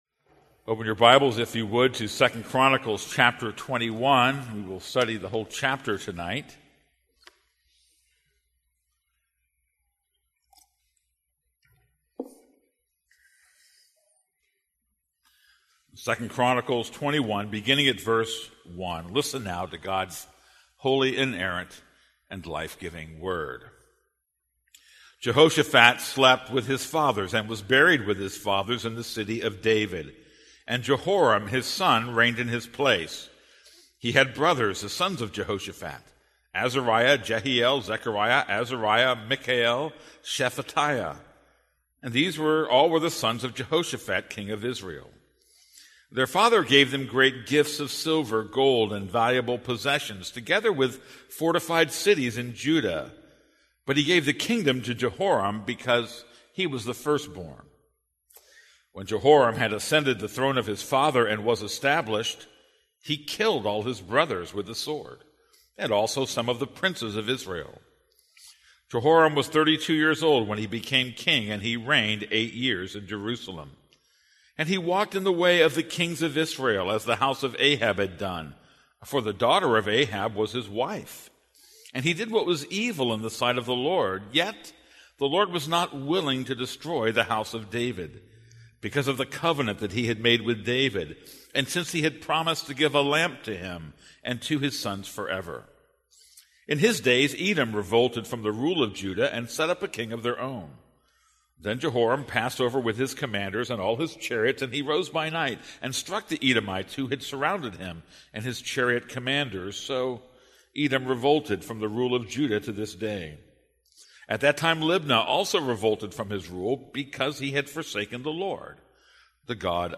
This is a sermon on 2 Chronicles 21:1-20.